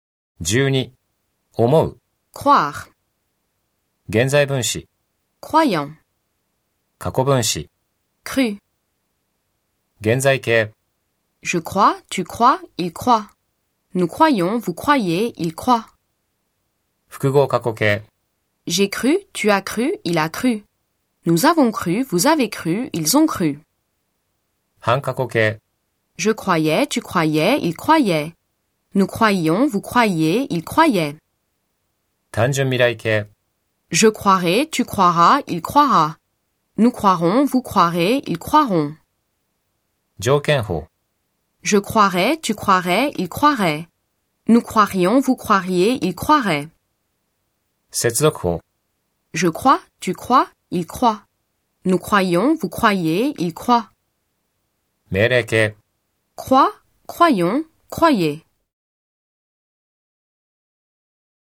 リズミカルでしょ？